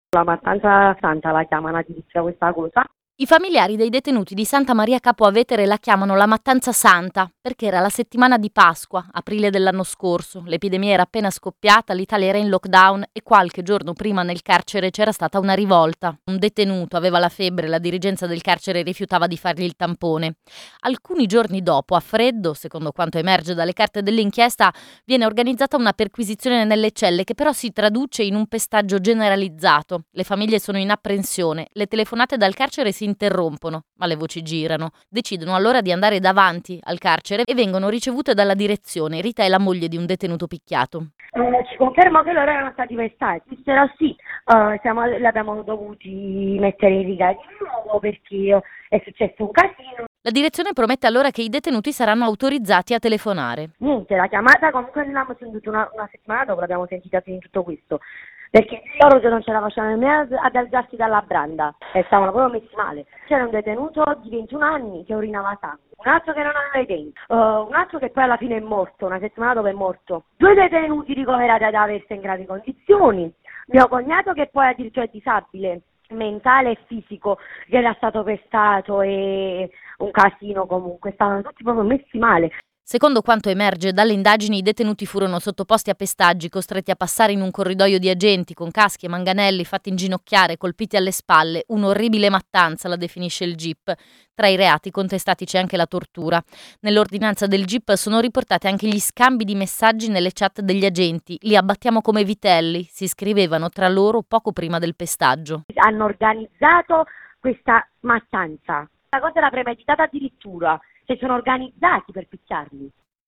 la testimonianza